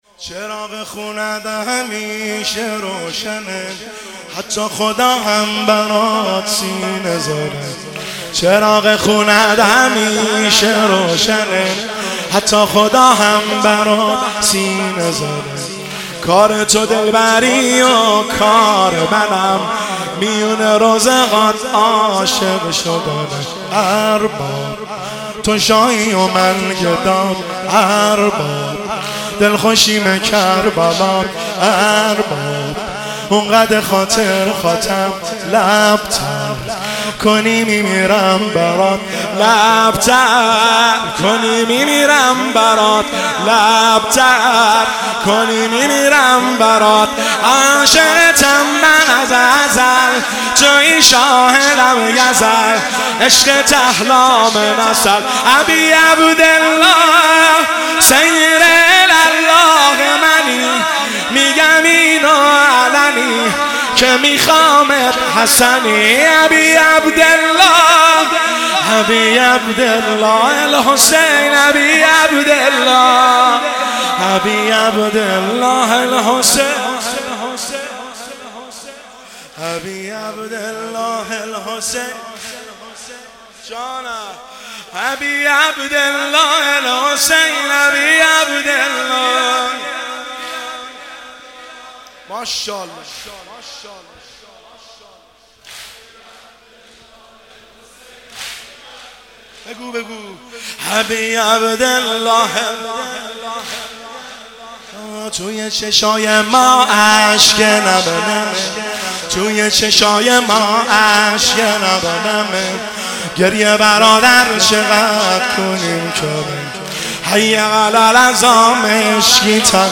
مداحی واحد شب اول محرم